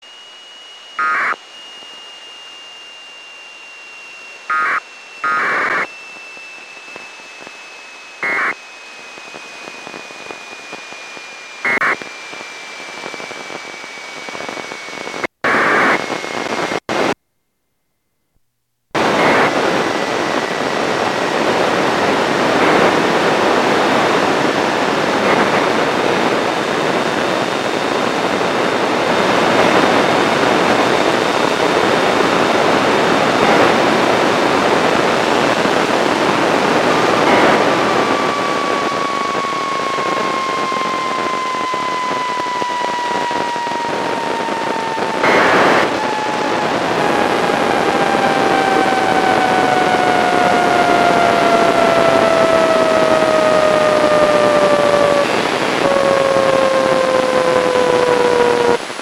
The Doppler starts high and then proceeds downward at an ever increasing rate as it was approaching the center of the pass.
An interesting deviation from the classic "S" curve of Doppler is the slight inflection in the audio rate at the start of the pass.
This long Doppler was a big surprise.